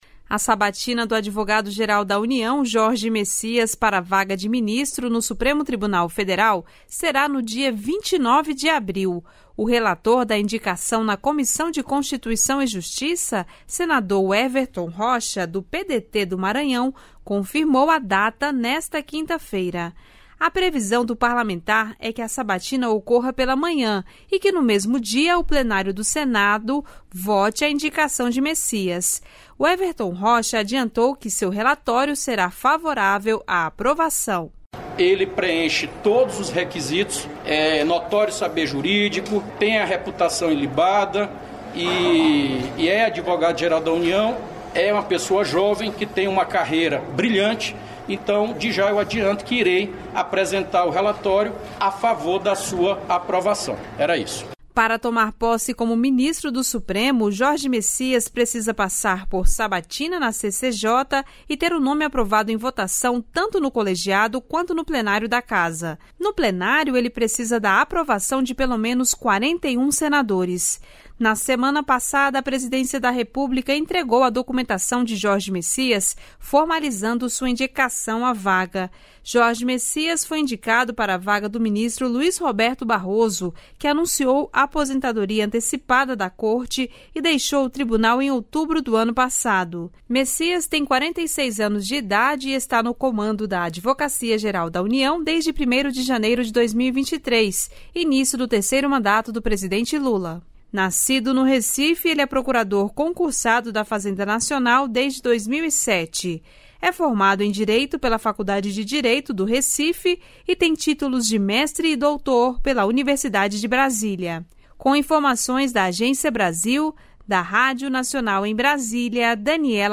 Matérias em Áudio